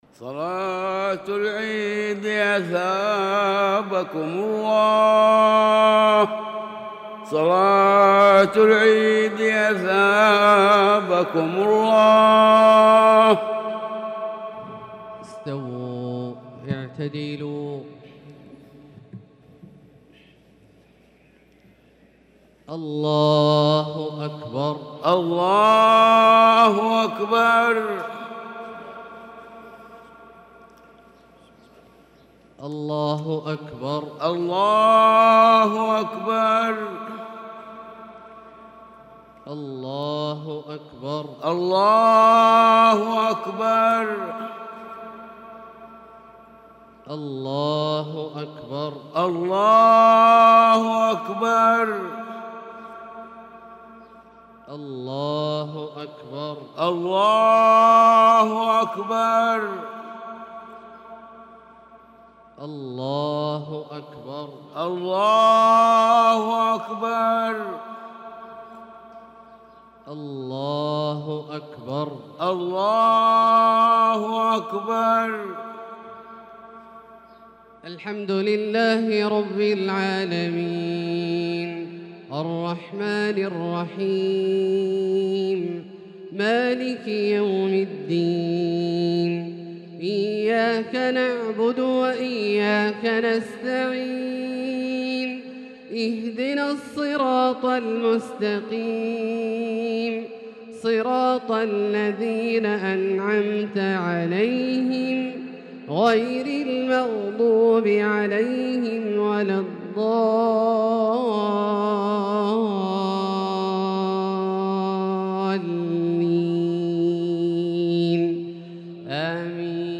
صلاة عيد الأضحى 1443هـ سورتي الأعلى والغاشية | Eid al-Adha prayer surah AlA'la & AlGhashiyah 9-7-2022 > 1443 🕋 > الفروض - تلاوات الحرمين